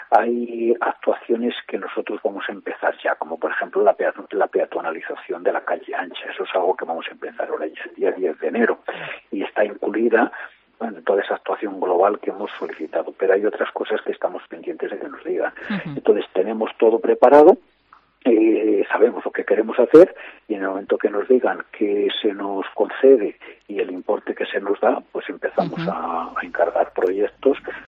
Fernando Carabias, concejal de Tráfico en el Ayuntamiento de Salamanca